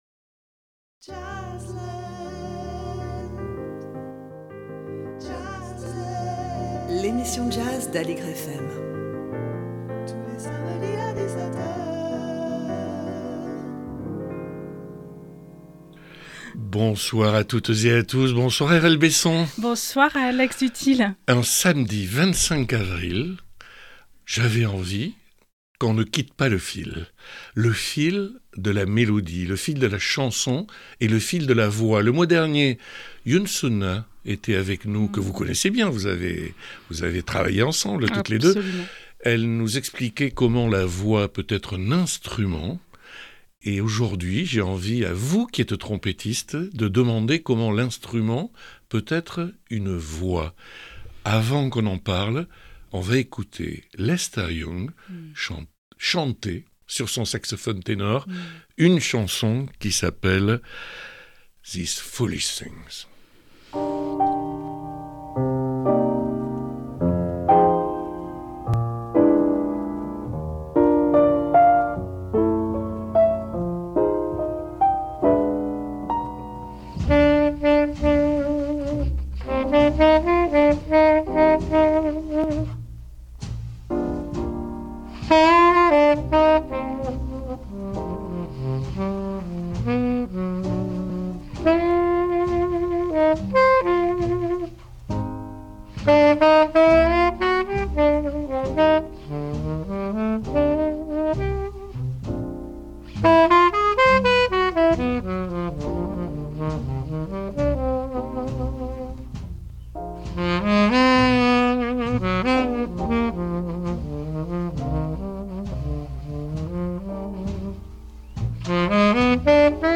Pour en parler, Alex Dutilh reçoit la trompettiste Airelle Besson